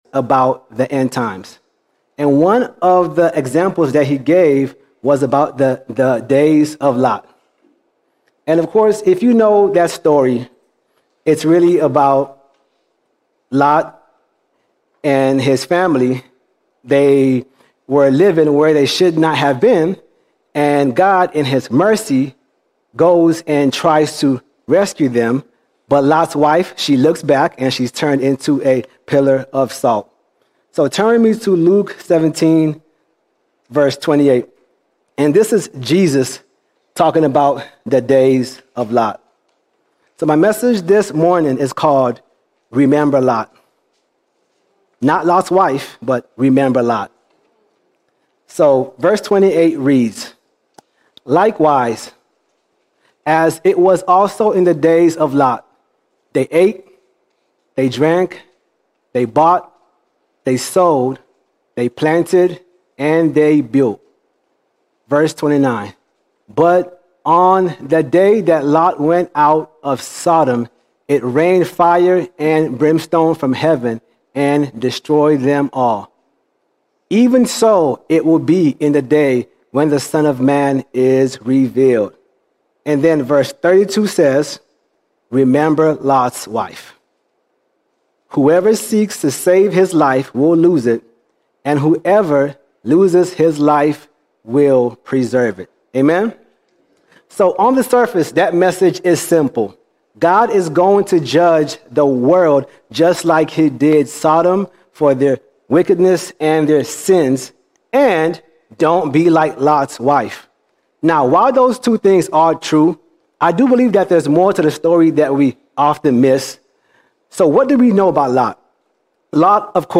21 July 2025 Series: Sunday Sermons All Sermons Remember Lot Remember Lot Although we are justified, like Lot, we can drift from the word of God and that distance can lead to compromise and loss.